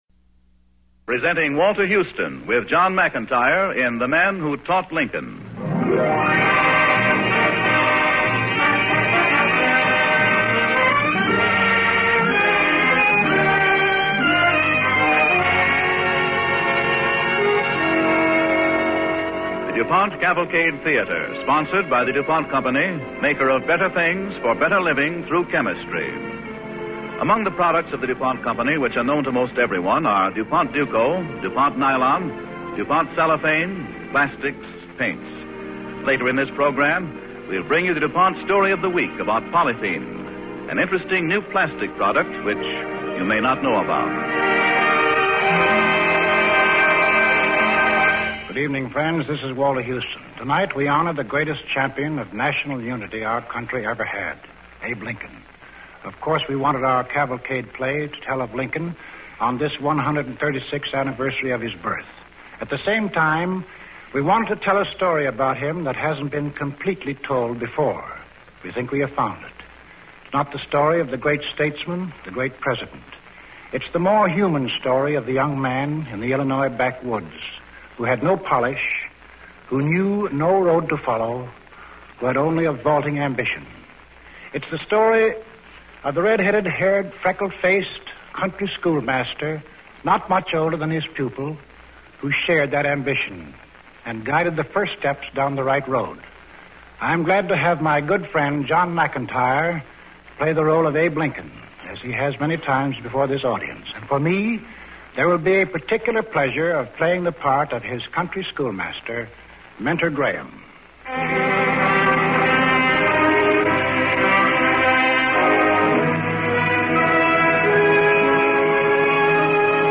starring and hosted by Walter Houston